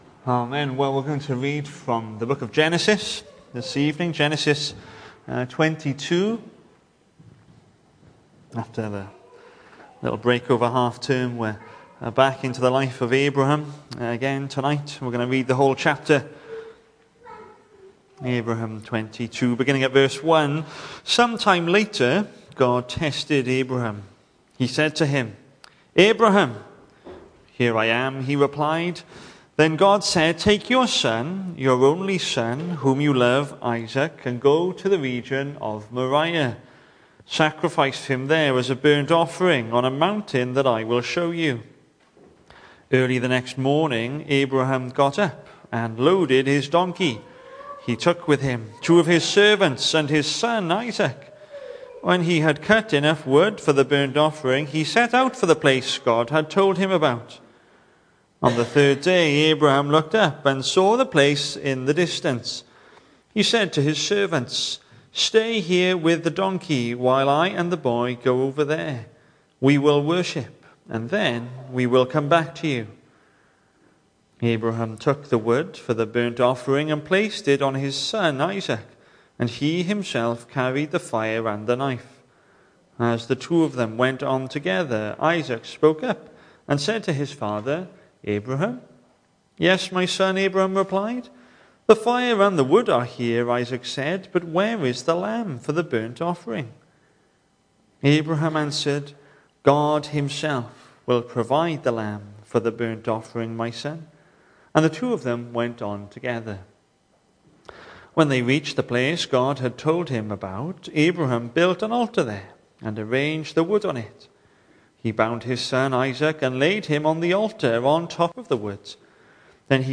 Hello and welcome to Bethel Evangelical Church in Gorseinon and thank you for checking out this weeks sermon recordings.
The 1st of March saw us hold our evening service from the building, with a livestream available via Facebook.